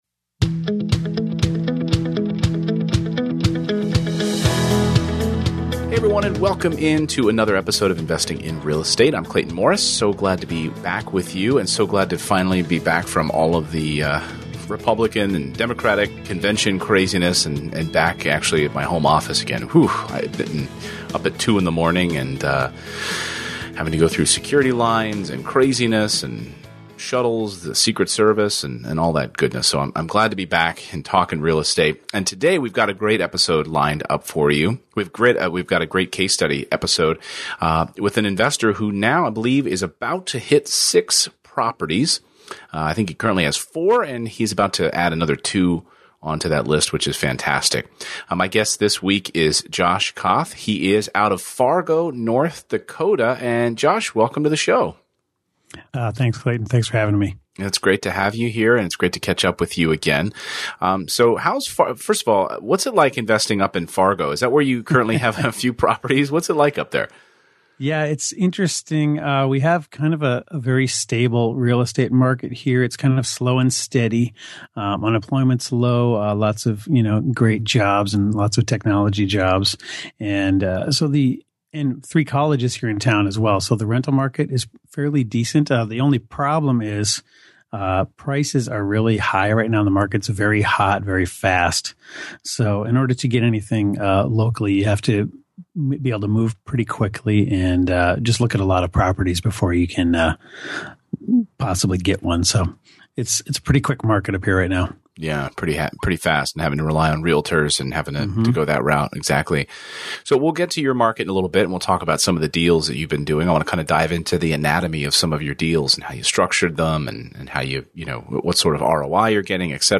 There is a better way, and today’s guest is here to share how he’s earning passive income in order to build a safer an...